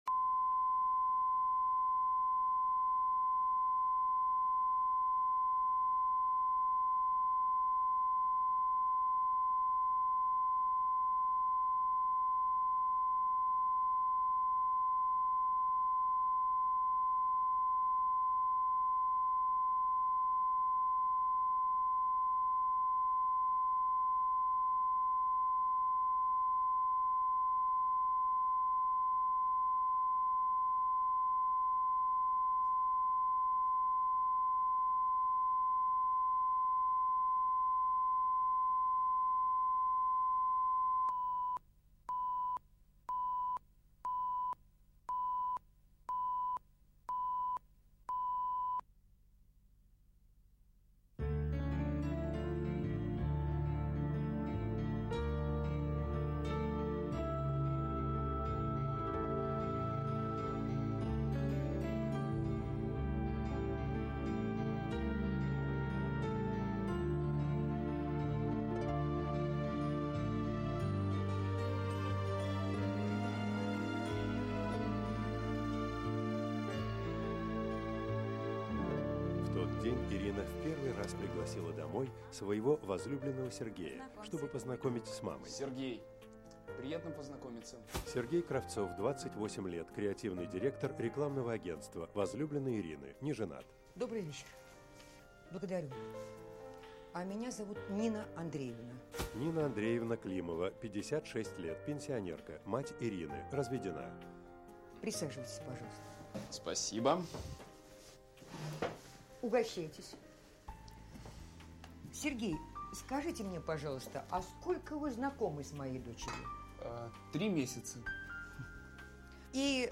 Аудиокнига Полюбить красивого